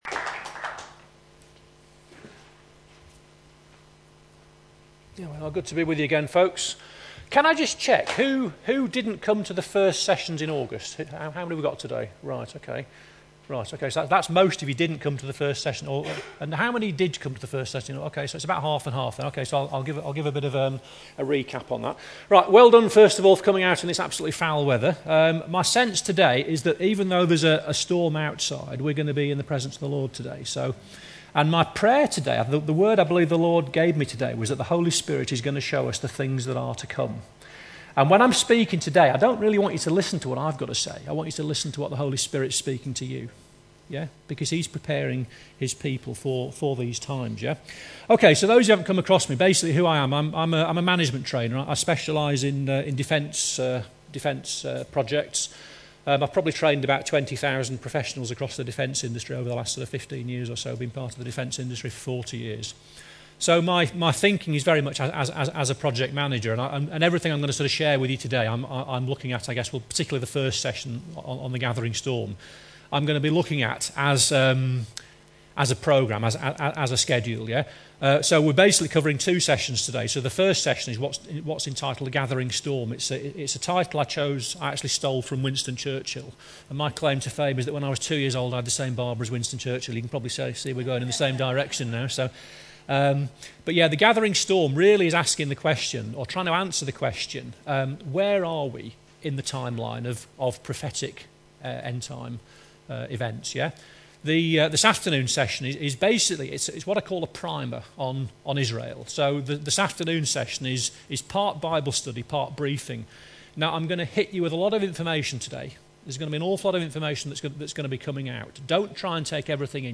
You can listen to Module 3 Part #1 here, recorded at the UK International Embassy Jerusalem Prophetic Conference, Wigan 2015.